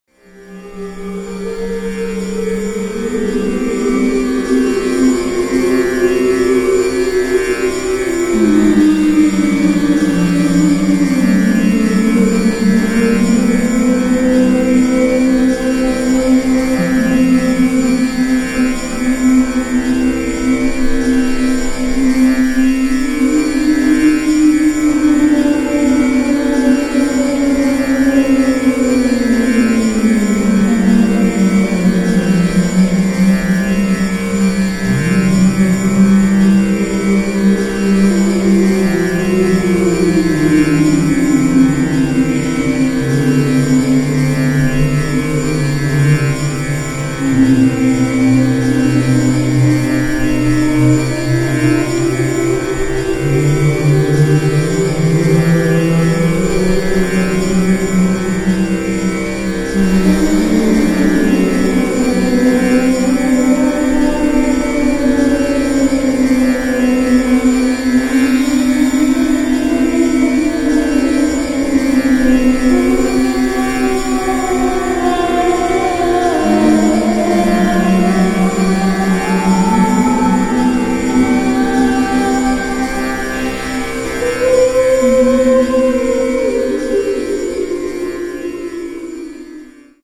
等著你／楊定一 (4CD) 【Disc 1】 曲目 長度 1. 楊定一博士 講述 04:49 2.